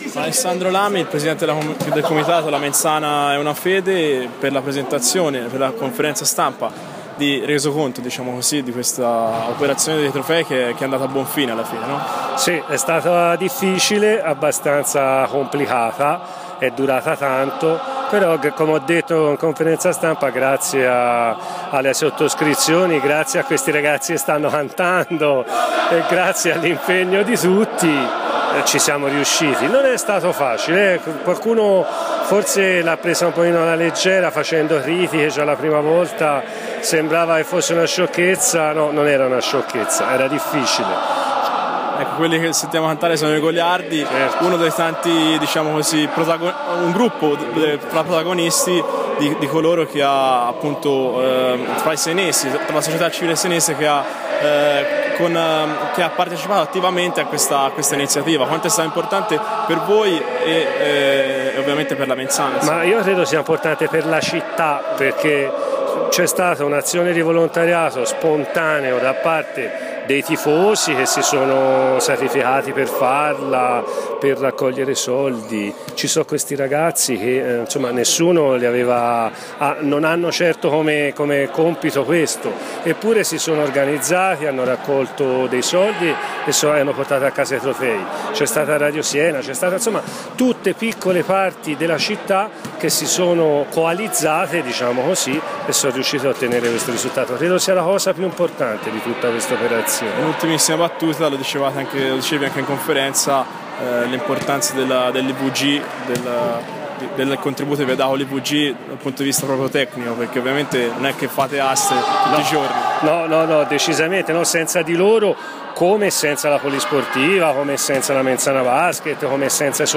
Si è svolta questa mattina presso la Presidenza Storica della Polisportiva la conferenza stampa relativa alla riacquisizione dei trofei della vecchia Mens Sana Basket.